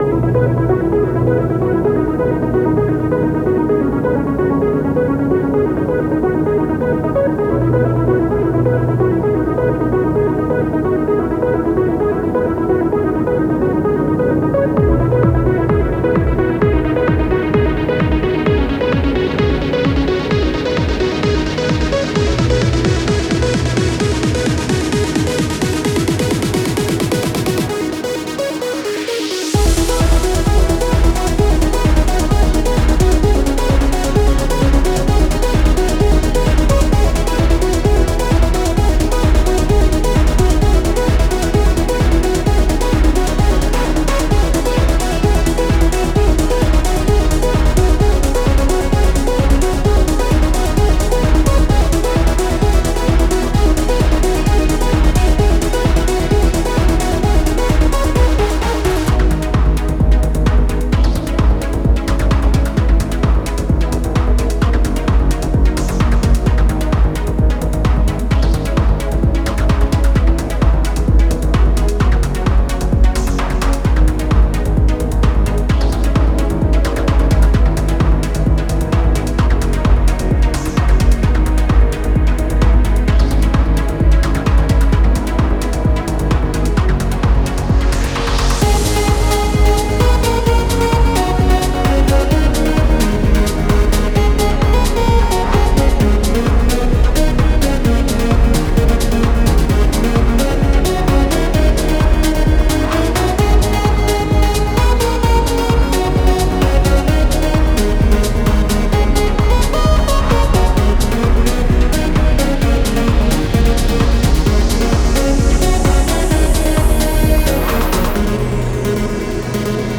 Trance Music for dungeon theme.